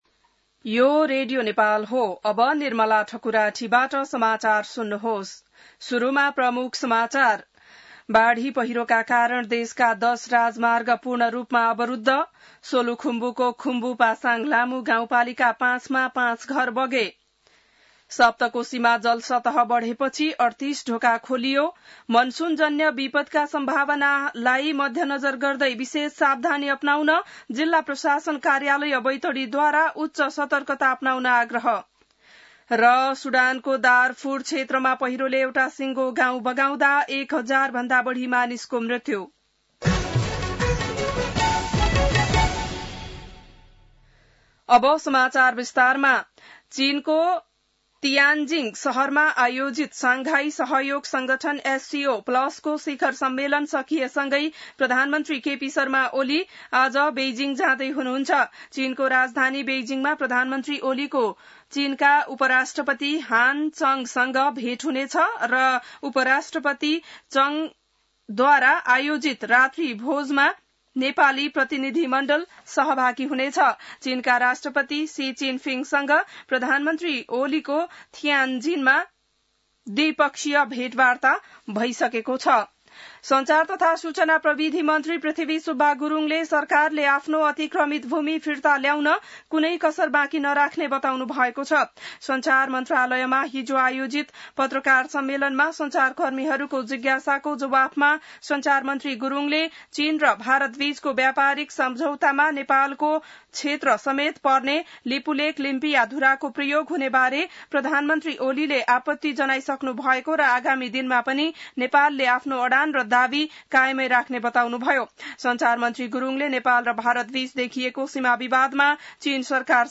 बिहान ९ बजेको नेपाली समाचार : १७ भदौ , २०८२